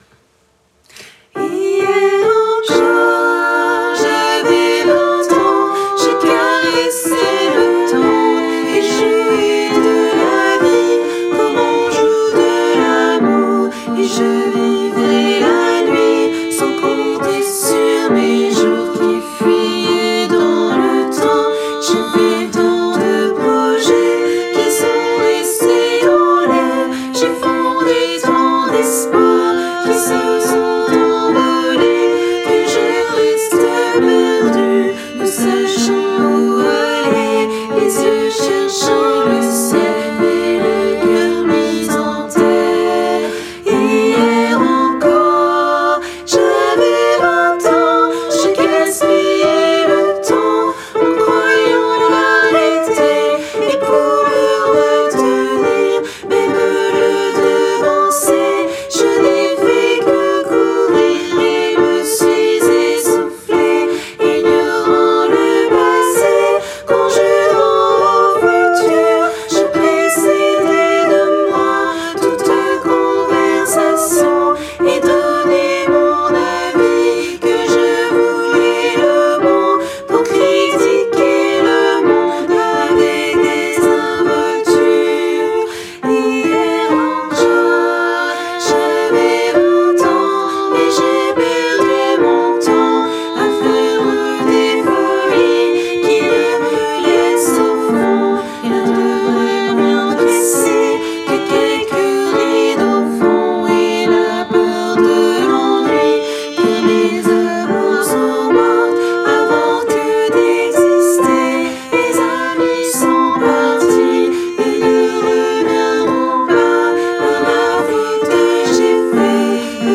- Chant pour chœur mixte à 3 voix (SAH)
MP3 versions chantées
Tutti